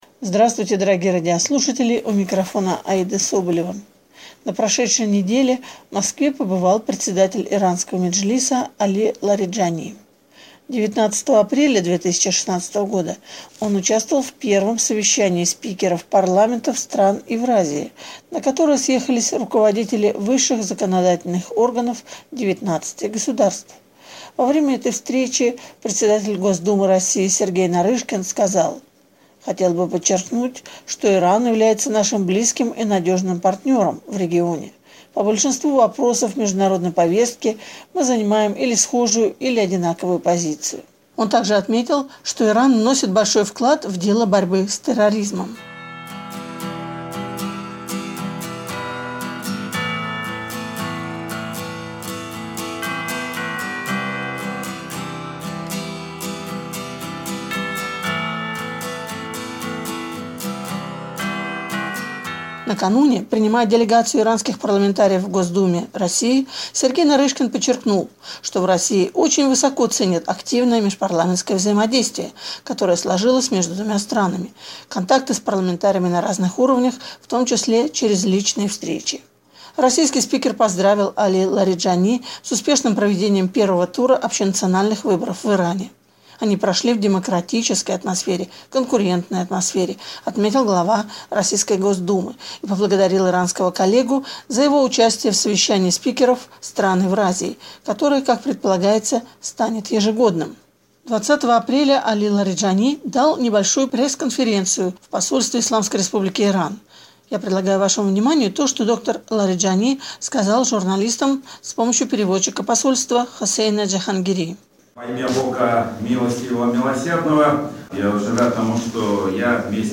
Пресс-конференция главы иранского парламента